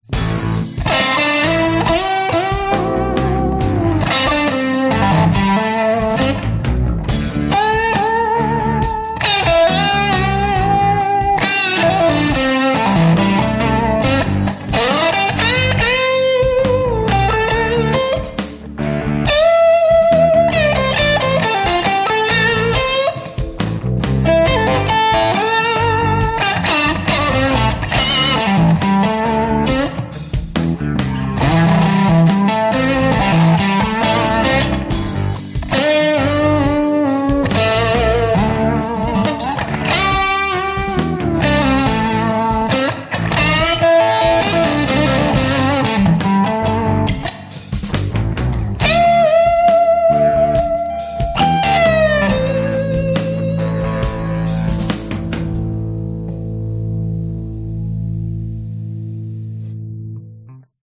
一个镜头，试着按照70 bpm的节奏轨道演奏，虽然它没有量化或编辑。
标签： 钢琴 一个_shots 平滑 寒意 低速 圆润
声道立体声